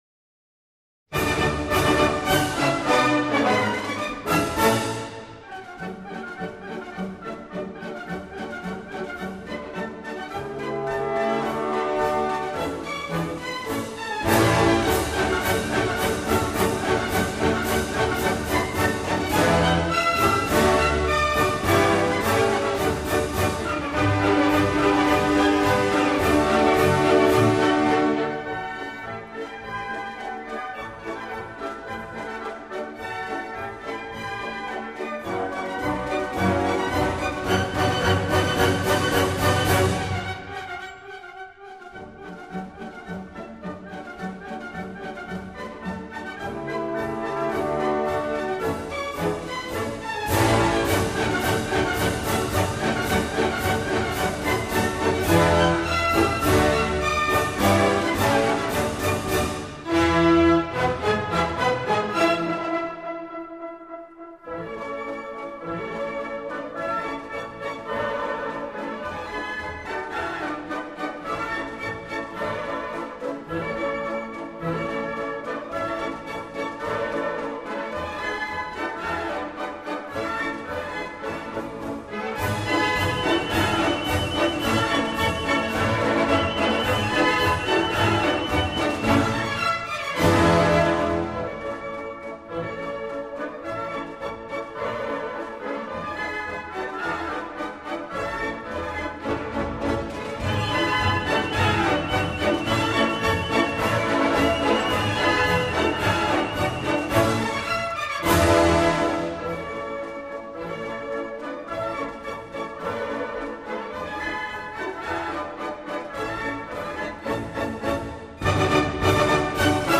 Tape-recording:Berlin Philharmonic Hall,1981